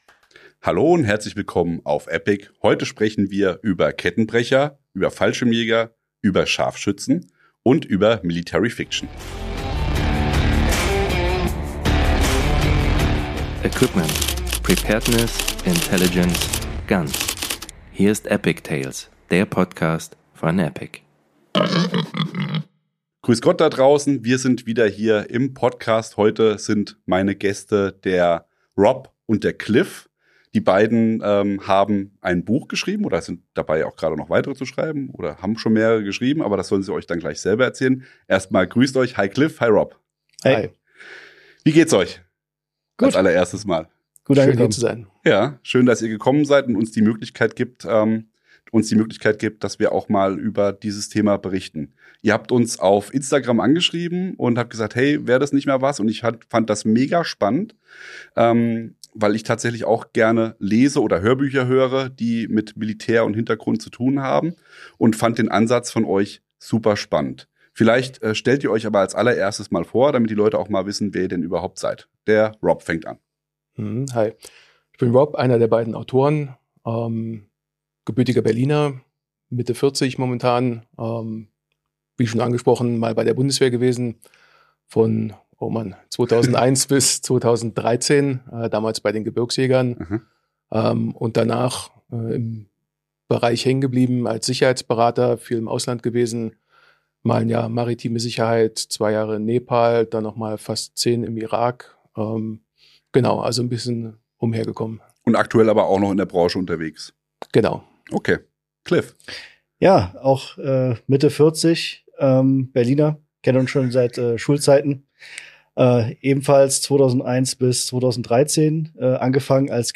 ETP015 - Guesttalk- "Military-Fiction" Im Gespräch mit Veteranen. ~ ePIG-TAILS - der PODCAST! Podcast